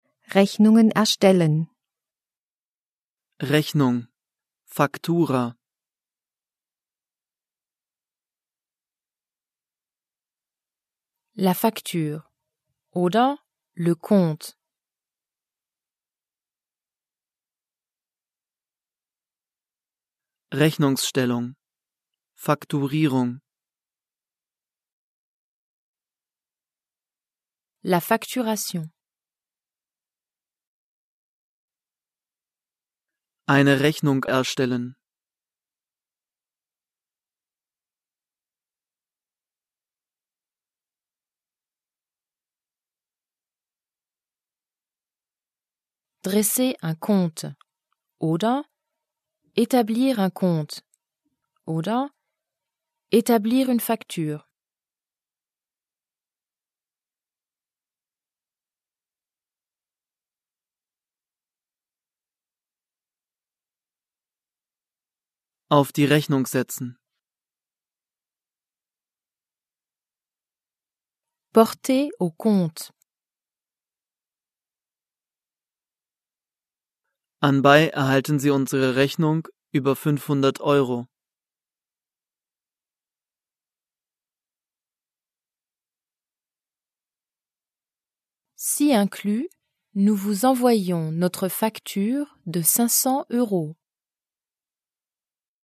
Es sind die Wörter erfasst, die man im Geschäftsleben häufig braucht.Er ist zweisprachig aufgebaut (Deutsch - Französisch), nach Themen geordnet und von Muttersprachlern gesprochen. Übersetzungs- und Nachsprechpausen sorgen für die Selbstkontrolle.